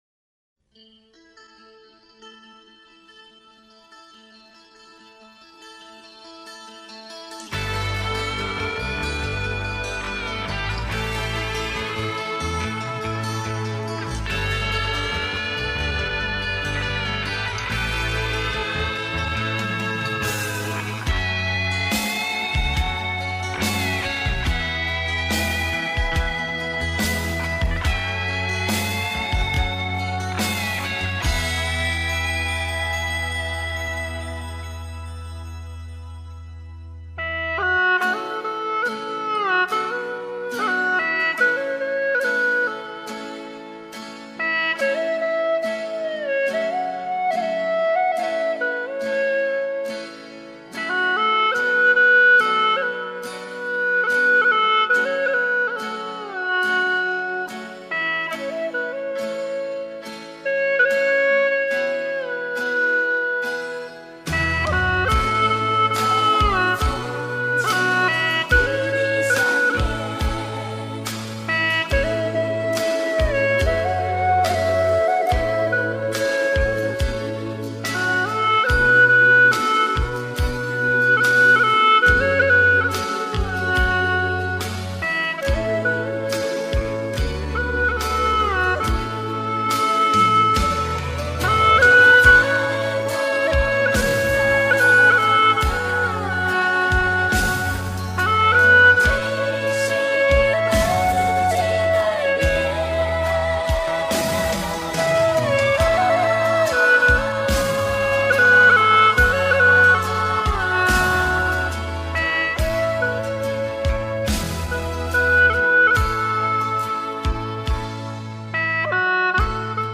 曲类 : 流行